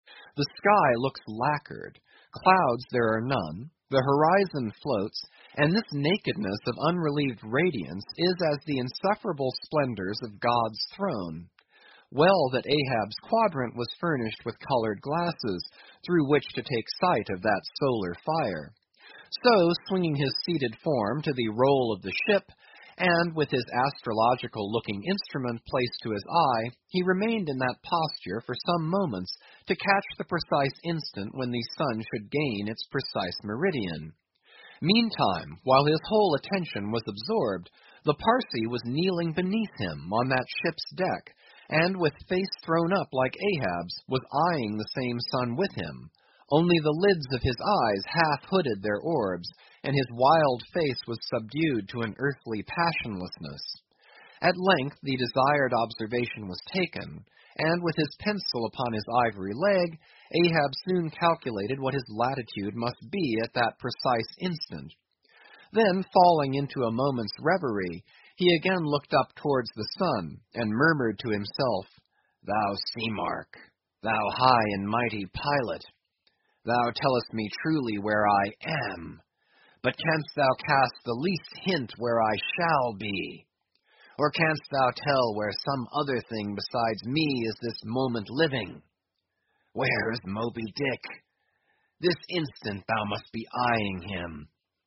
英语听书《白鲸记》第934期 听力文件下载—在线英语听力室